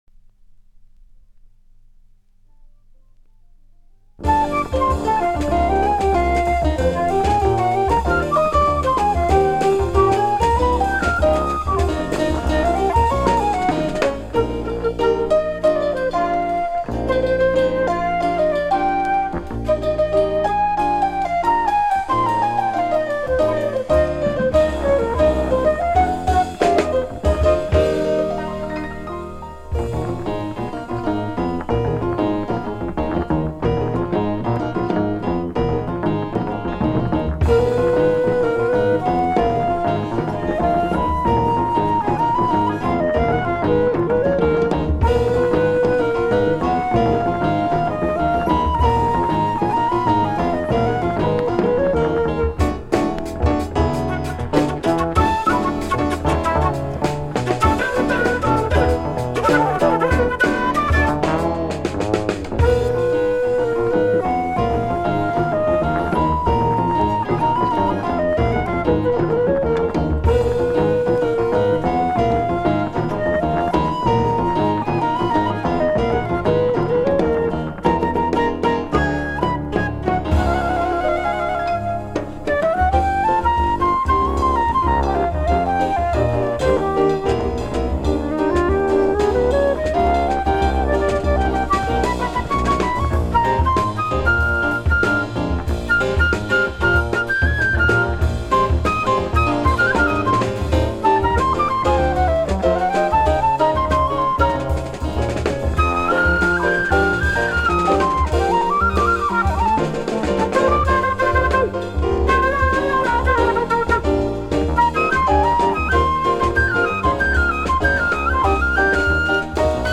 alto saxophone/flute*
piano
bass
drums
Recorded: February at RG. Studio, Vicenza, Italy